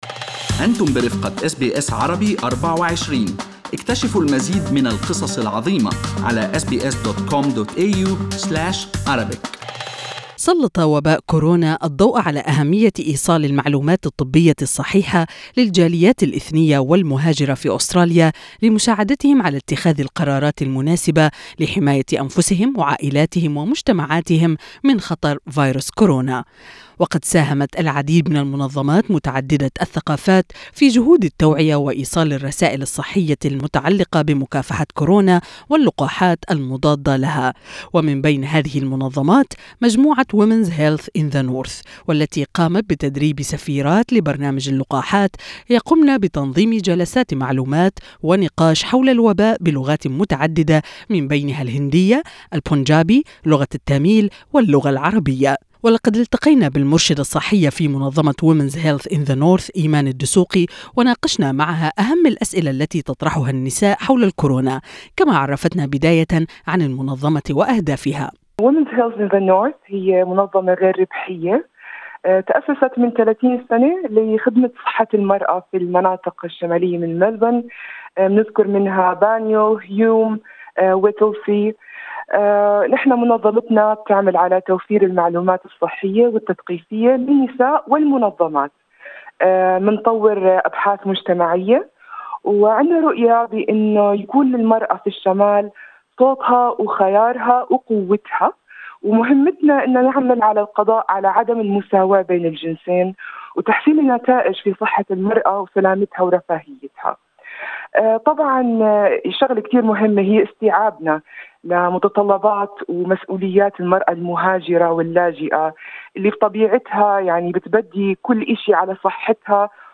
اللقاء الكامل